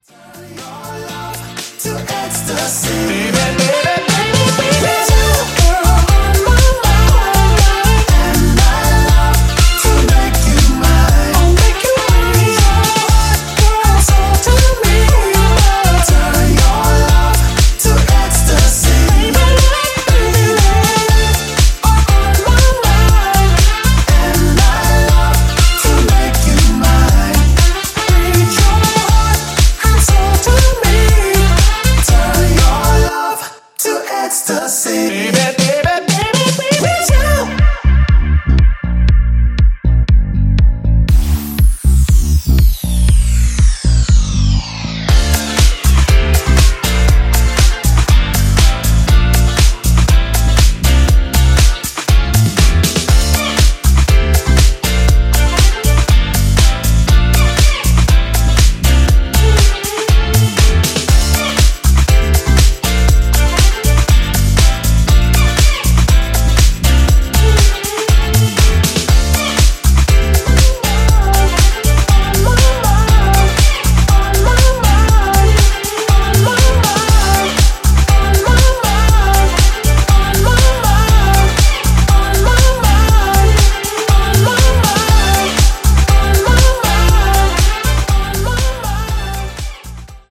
ジャンル(スタイル) DEEP HOUSE / DISCO HOUSE